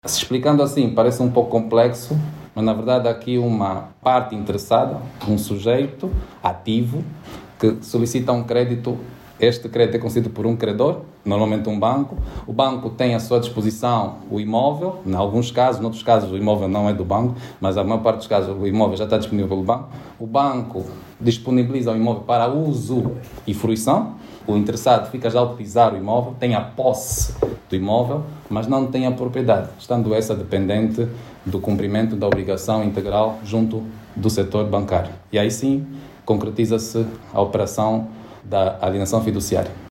O diploma estabelece mecanismos facilitados para acesso ao crédito imobiliário para fins habitacionais. O Secretário de Estado da Justiça, Osvaldo Amaro, explica que no contrato de alienação fundiária o imóvel mantém-se na titularidade do credor até que se conclua o cumprimento da obrigação.